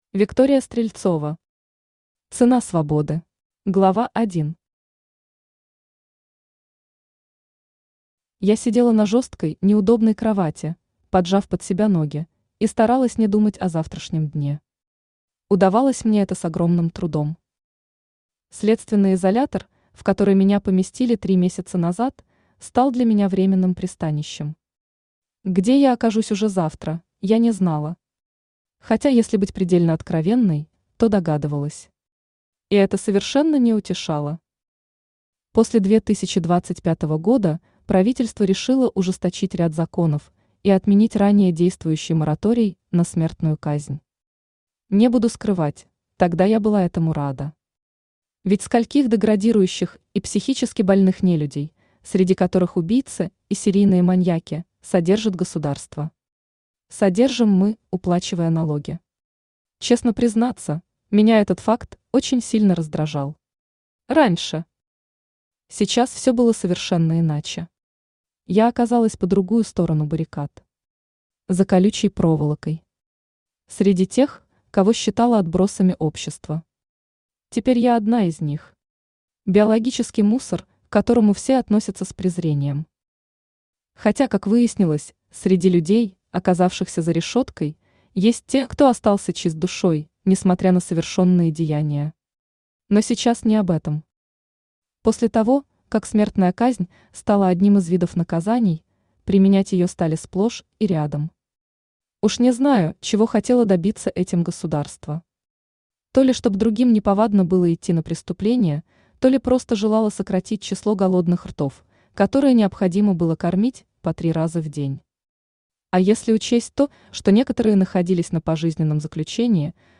Аудиокнига Цена свободы | Библиотека аудиокниг
Aудиокнига Цена свободы Автор Виктория Стрельцова Читает аудиокнигу Авточтец ЛитРес.